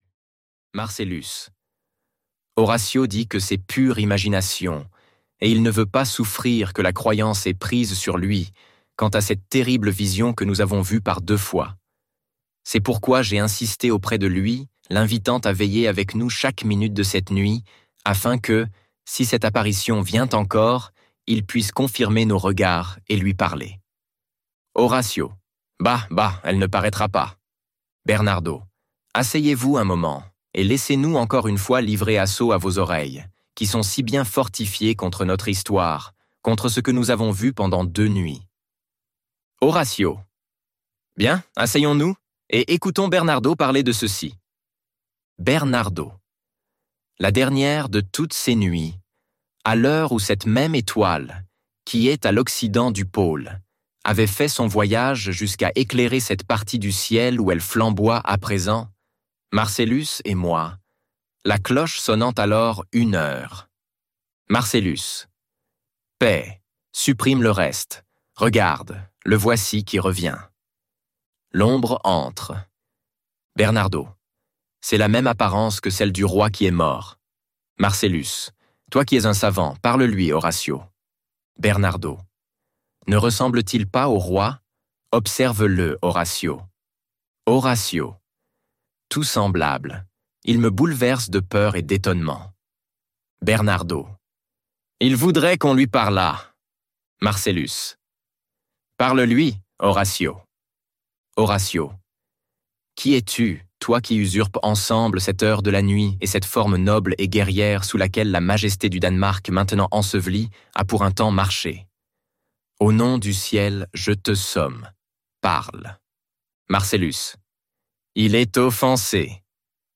Hamlet - Livre Audio